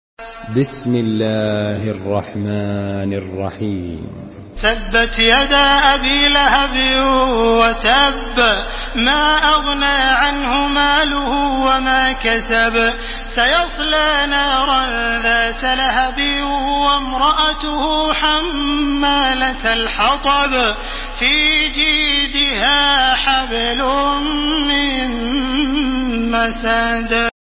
تحميل سورة المسد بصوت تراويح الحرم المكي 1431
مرتل